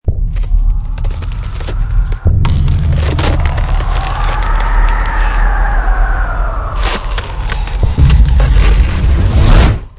Звук коллапса ледяной аномалии